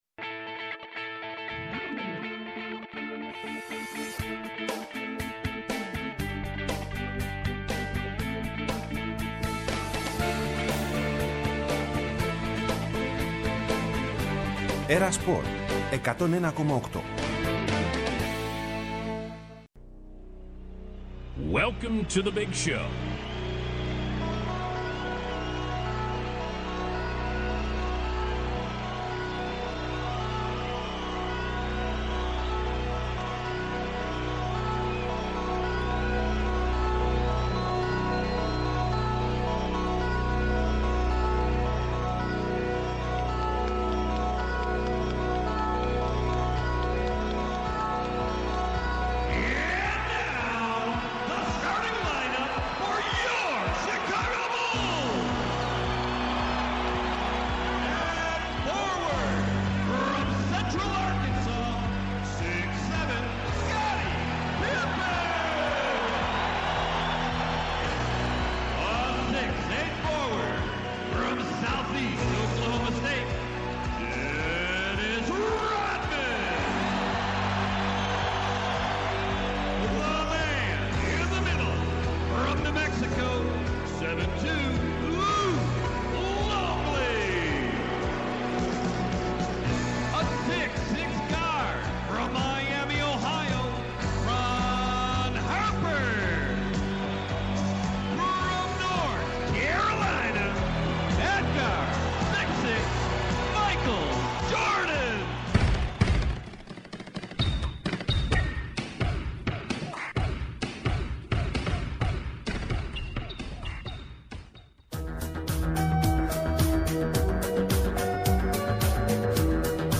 Η μοναδική, καθημερινή, εκπομπή μπάσκετ στα ερτζιανά.